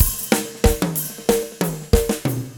14 rhdrm93snare.wav